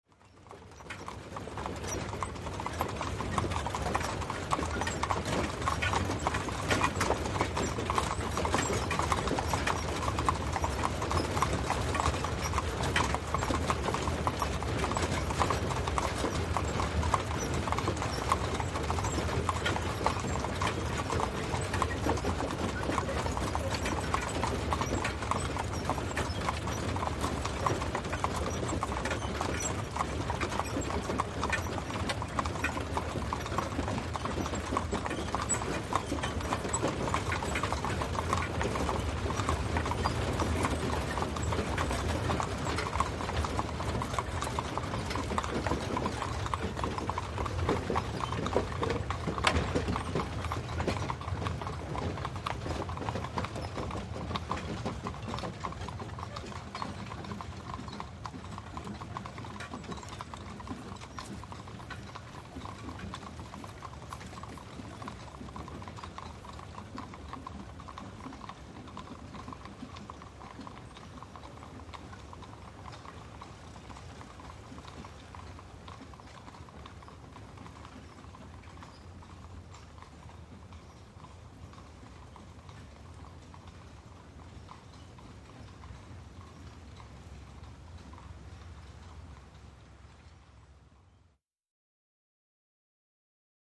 Caisson By Cu, Away Into Distance On Hard Surface W Wagon Movement.